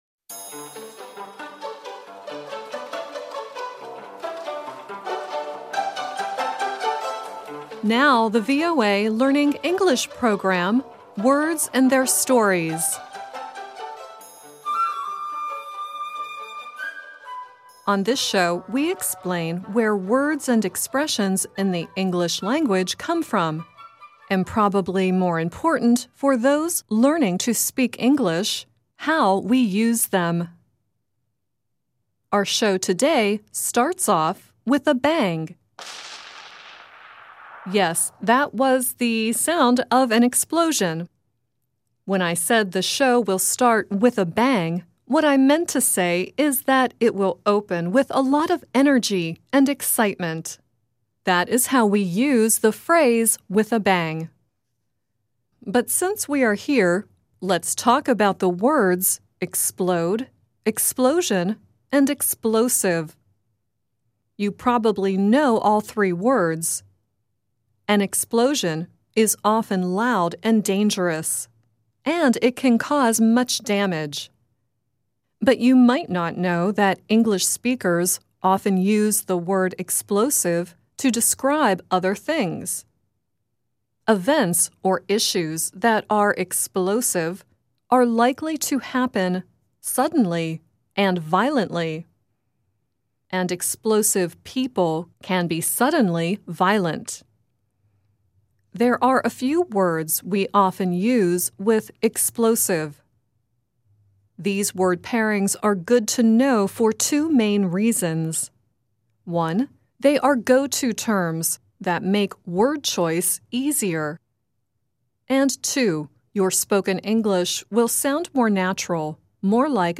The song at the end is Devo singing "We Like Explosions."